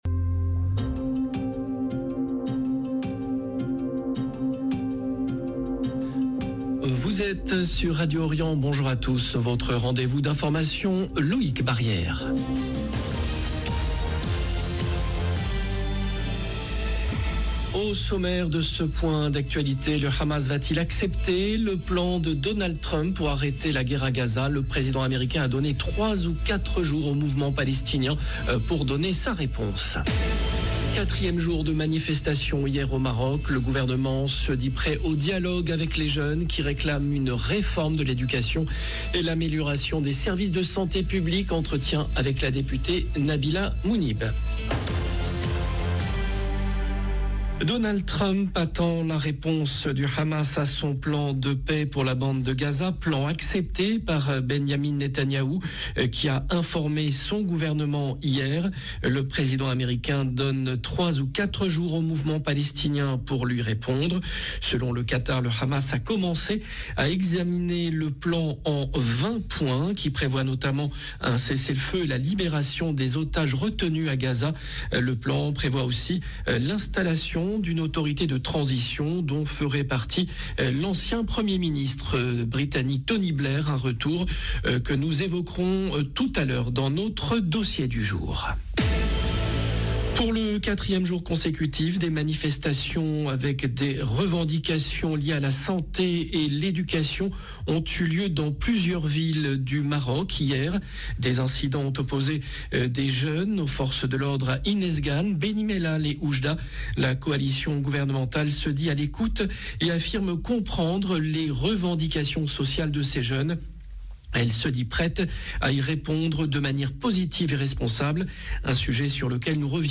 JOURNAL DE MIDI DU 01/10/2025
Le président américain a donné 3 ou 4 jours au mouvement palestinien pour donner sa réponse. 4e jour de manifestations hier au Maroc. Le gouvernement se dit prêt au dialogue avec les jeunes qui réclament une réforme de l’éducation et l’amélioration des services de santé publique. Entretien avec la députée Nabila Mounib. 0:00 11 min 5 sec